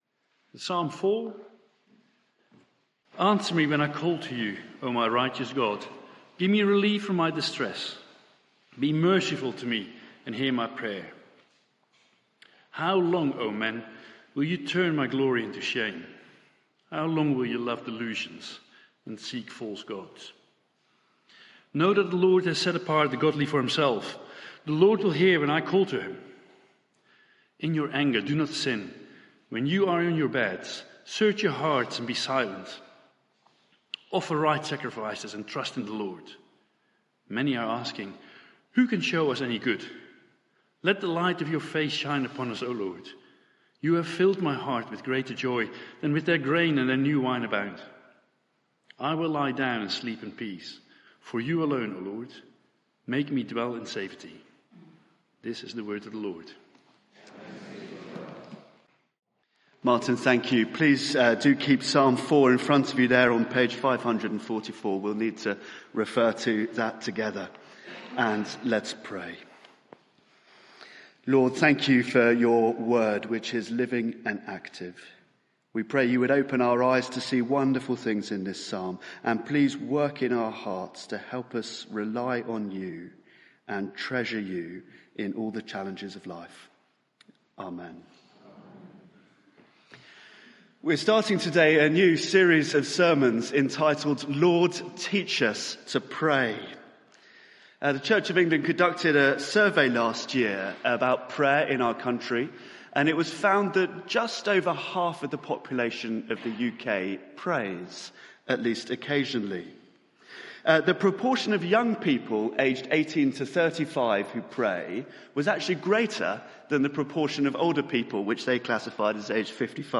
Media for 9:15am Service on Sun 23rd Jul 2023 09:15 Speaker
Sermon (audio)